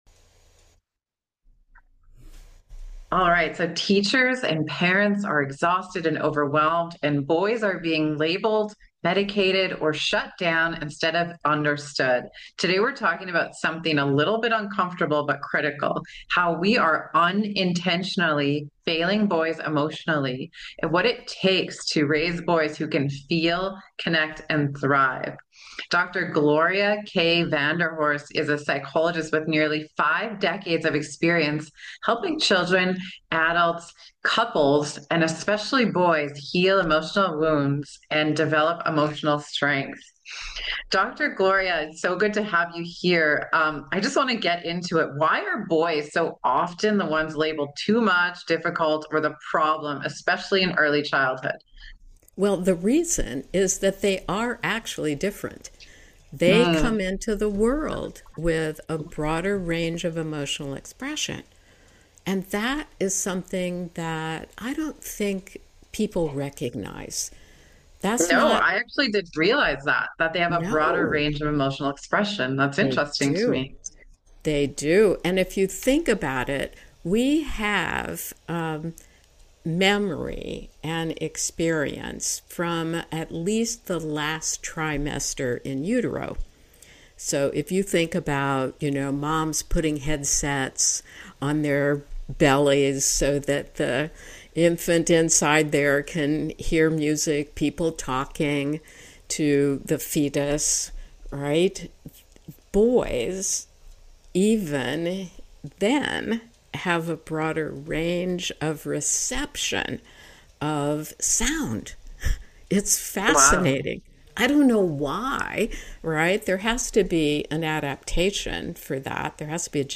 talks with psychologist